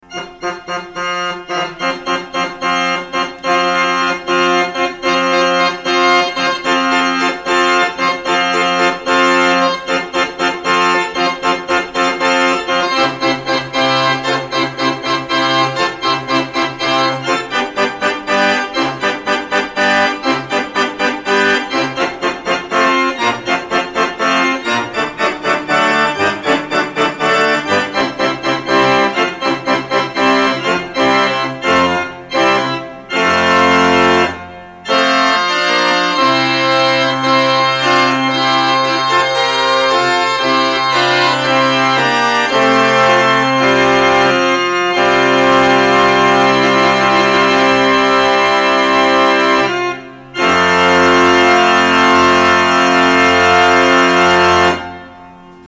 Konzert am 5. September 2009 in Bolea / Huesca
Die Aufnahmen wurde (heimlich) mit einem Handy (!) hinter der Orgel gemacht, der trockene Klang gibt also keineswegs die relativ lange Nachhallzeit in der Kirche wider, aber ein kleiner Eindruck mag doch entstehen, des vollen Zungenwerks am Ende der Batalla und der schönen Terz (Nazard) beim kleinen Stück von Sweelinck.